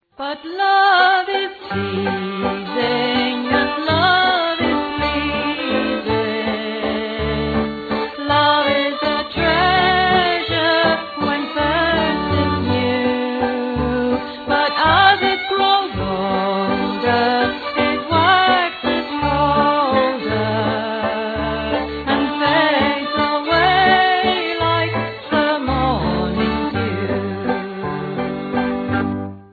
Love is Pleasing in a straight, no-frills way that few could fail to be enchanted by
I'm very pleased we are getting to hear a little more of her these days - it's a great voice ... and a super record.